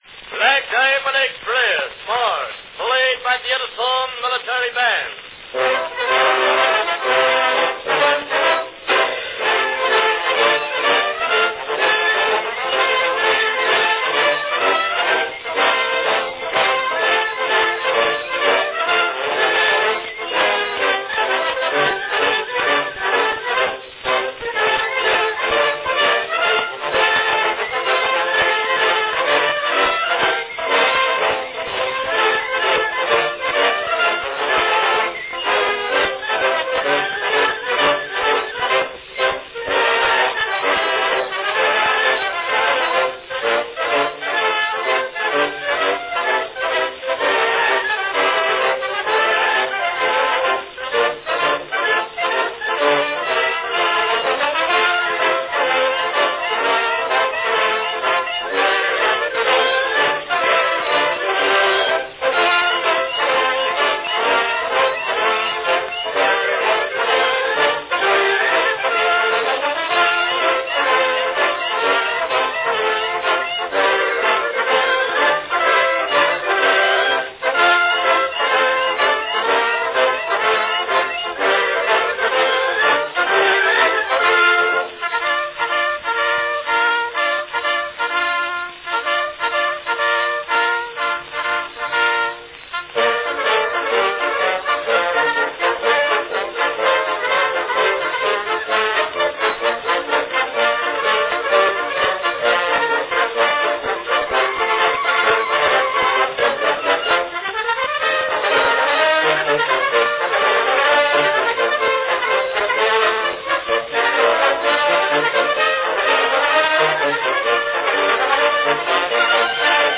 Cylinder of the Month
A lively band number from 1905 celebrating the famous Black Diamond Express train line.
Category Band
Performed by Edison Military Band
Enjoy this snappy band number inspired by the then state-of-the-art Black Diamond Express train line run by the Lehigh Valley Railroad.